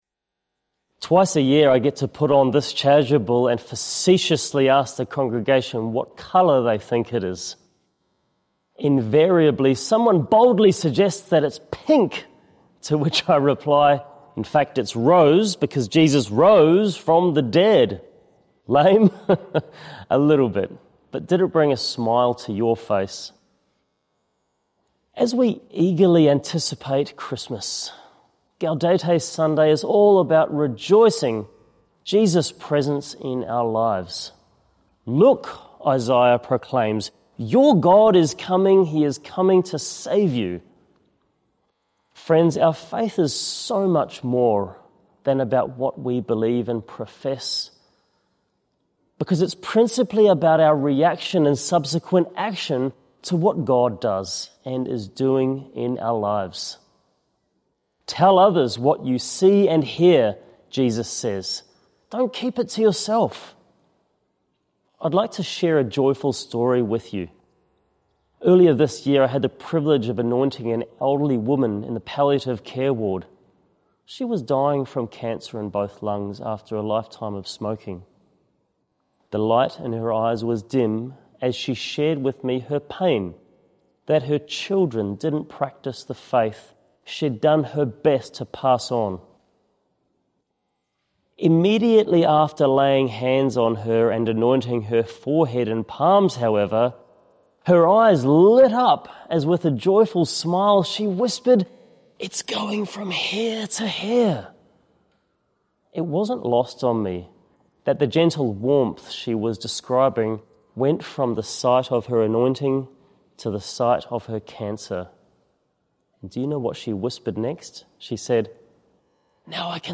Third Sunday of Advent - Two-Minute Homily